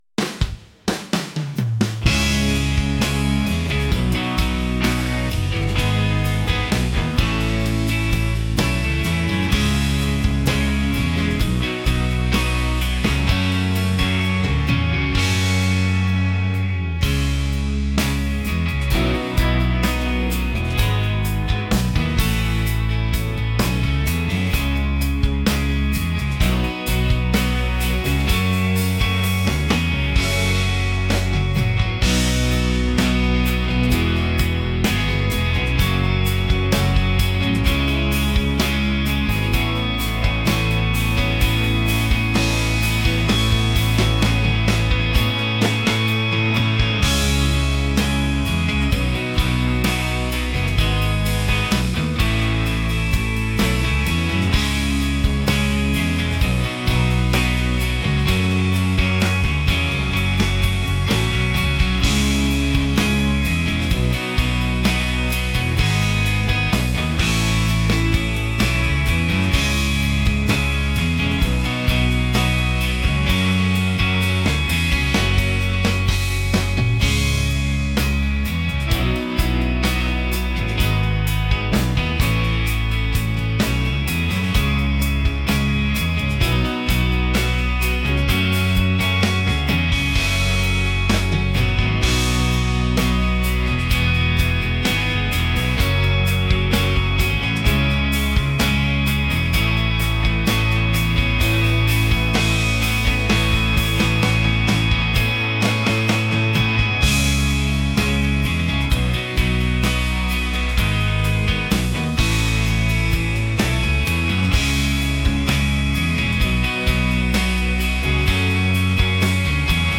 alternative | rock | soulful